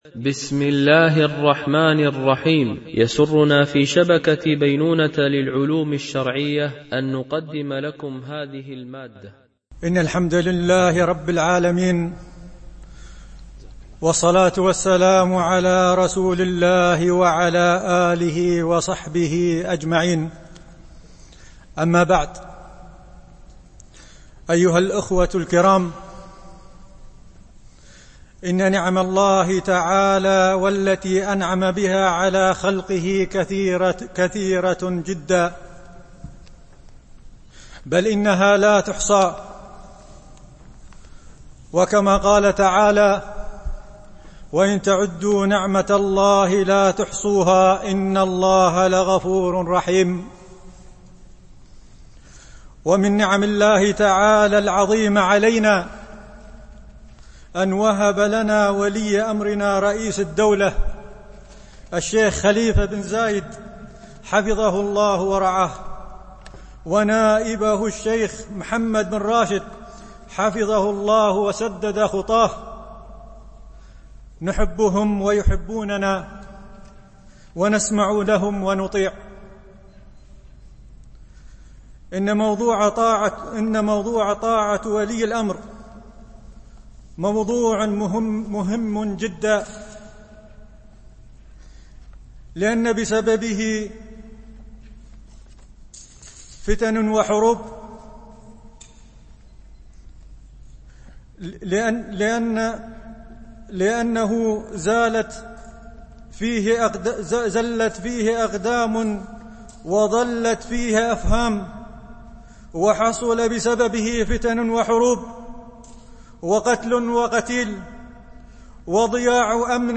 الشيخ: مجموعة من المشايخ القسم: لقاء مفتوح